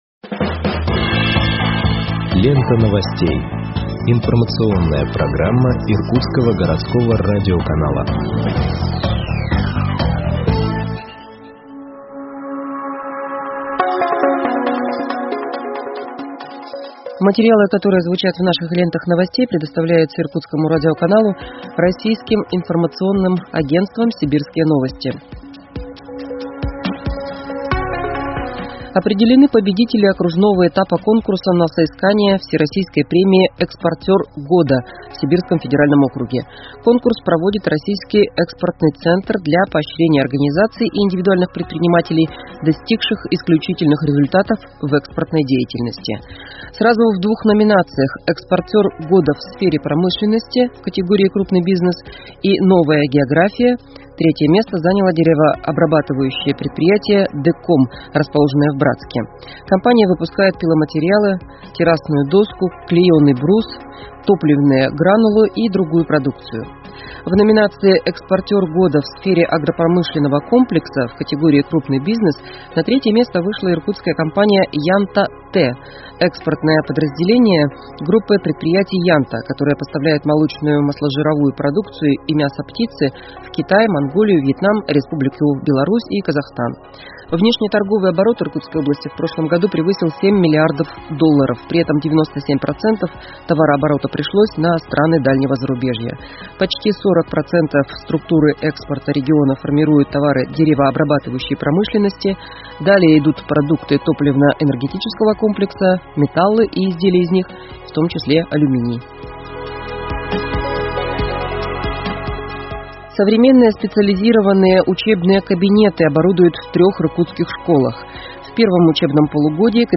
Выпуск новостей в подкастах газеты Иркутск от 31.08.2021 № 1